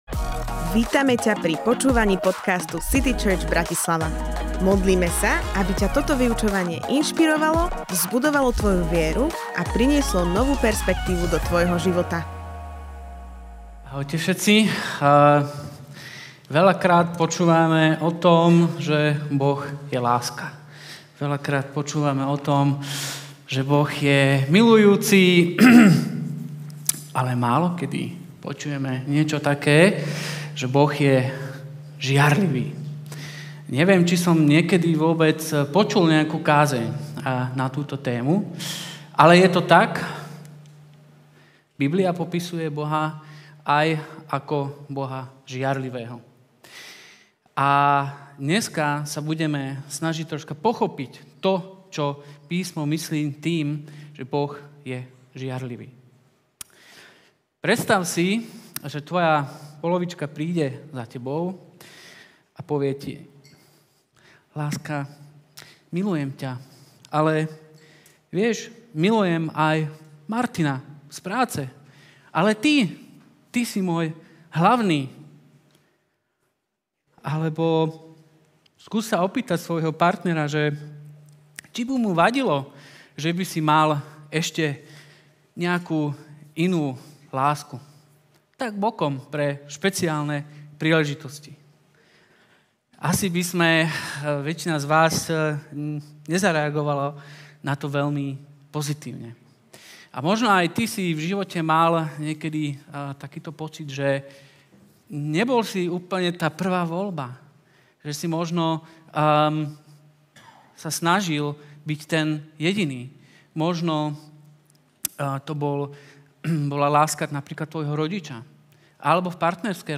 Boh je žiarlivý Kázeň týždňa Zo série kázní